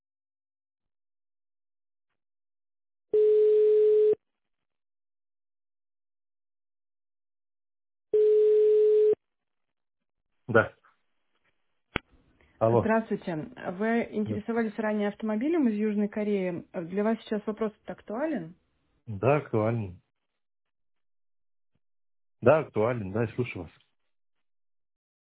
Текст озвучивается живым человеческим голосом, что вызывает доверие.
Примеры аудиозаписей "Звонка робота"